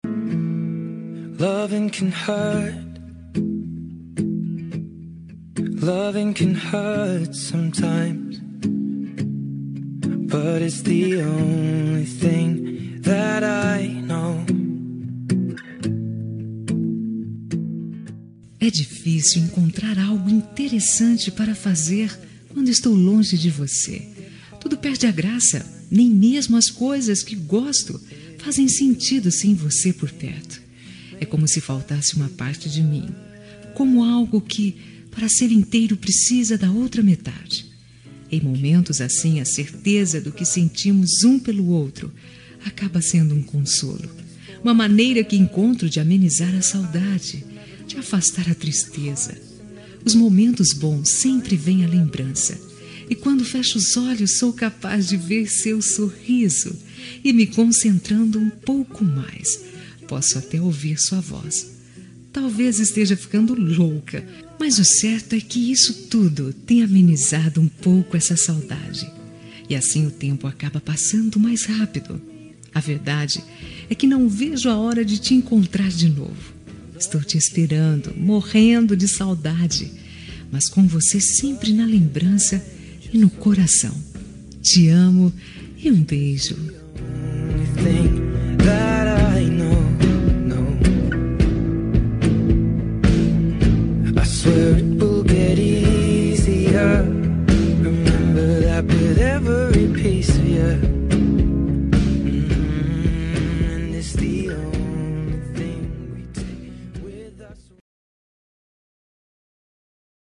Telemensagem de Saudades – Voz Feminina – Cód: 4142